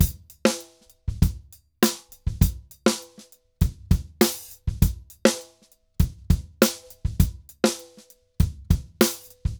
Drums_Candombe 100_2.wav